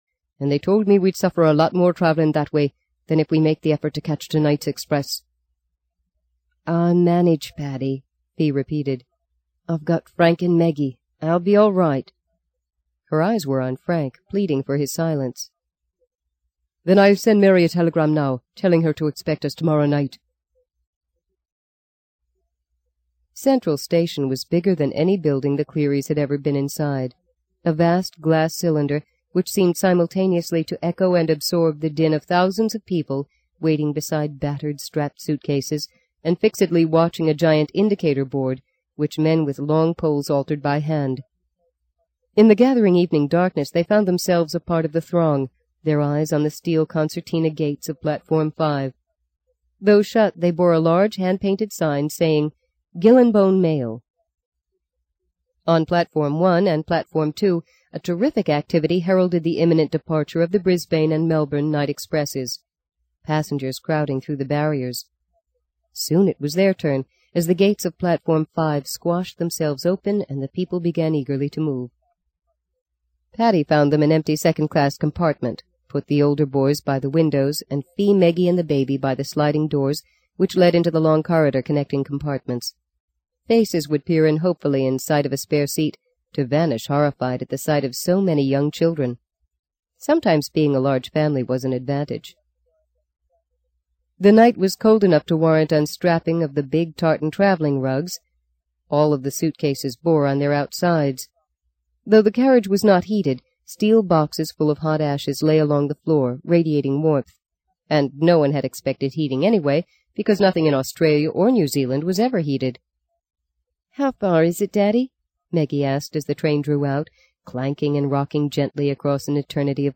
在线英语听力室【荆棘鸟】第三章 17的听力文件下载,荆棘鸟—双语有声读物—听力教程—英语听力—在线英语听力室